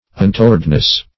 Un*to"ward*ness, n.